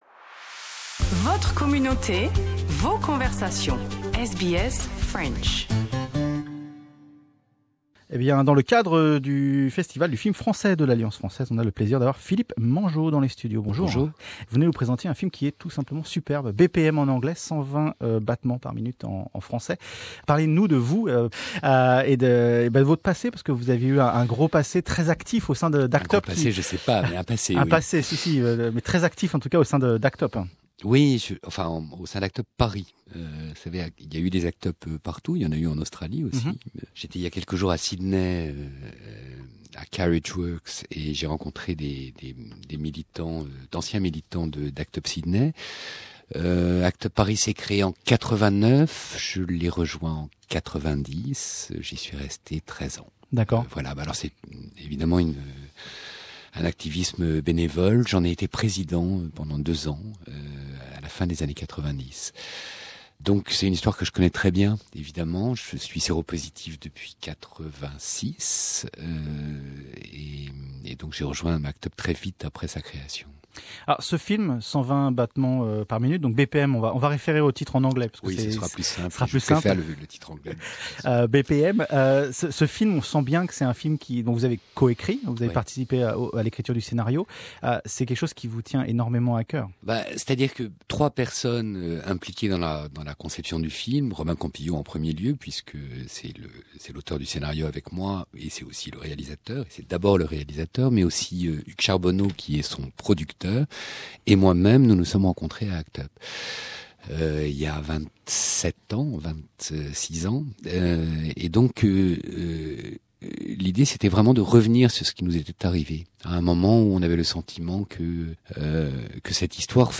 Cette interview date de mars 2018.